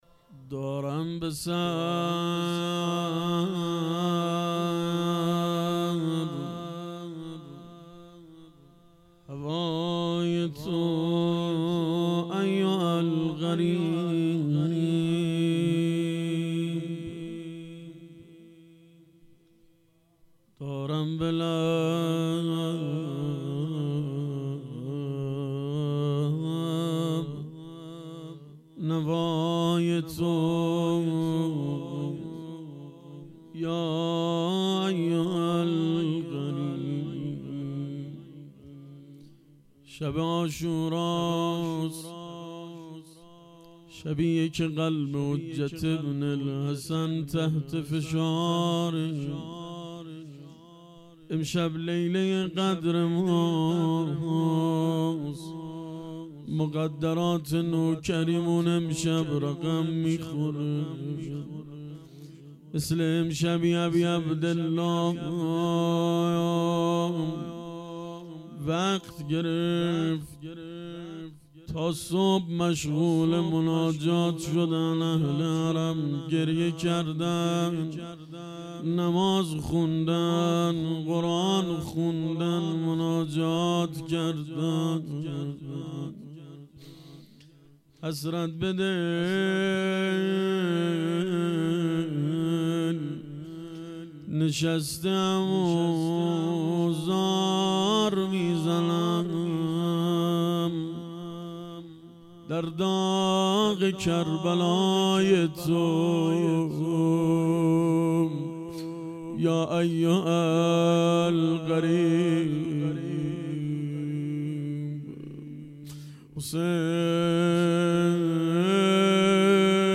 روضه شب دهم